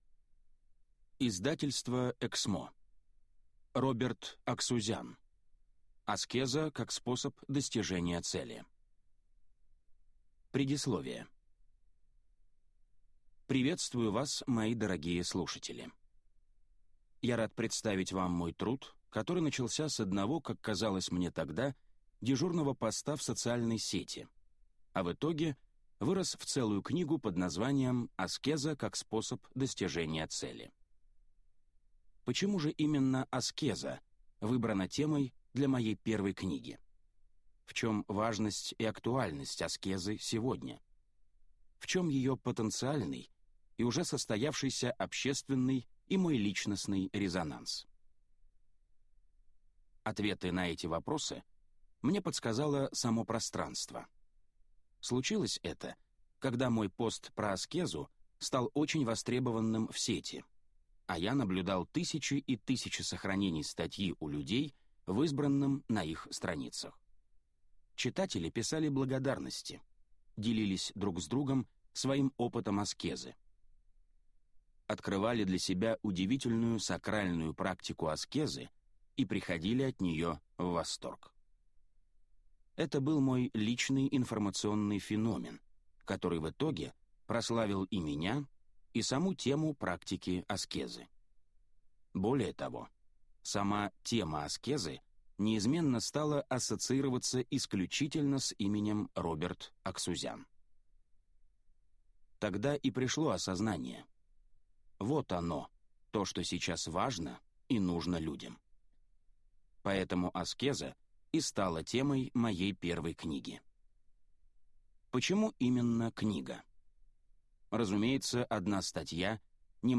Аудиокнига Аскеза как способ достижения цели | Библиотека аудиокниг